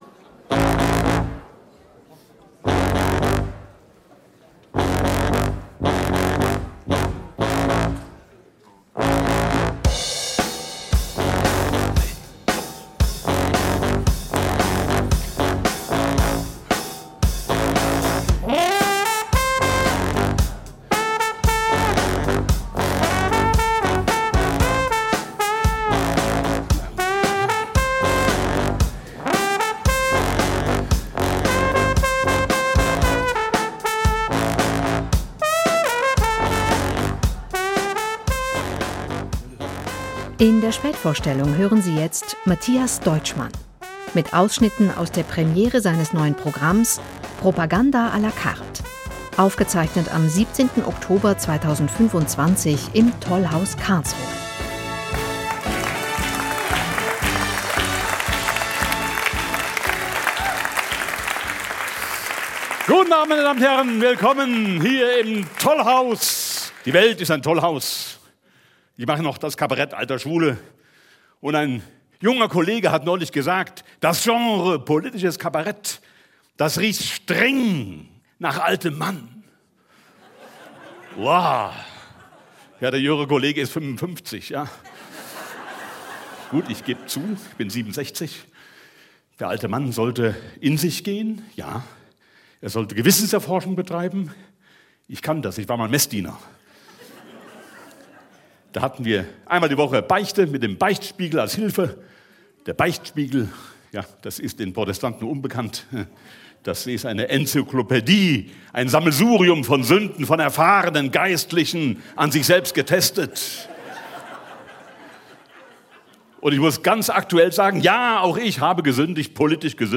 Deutschmann mit seinem Markenzeichen, dem Cello, zieht bissige, kluge und bisweilen sarkastische Bilanz auf eine Weise, wie es auf deutschen Kabarettbühnen selten geworden ist.
Premiere mit Propagandafrüherkennung und fröhlicher Entsorgung(Aufzeichnung vom 17. Oktober 2025 im TOLLHAUS Karlsruhe)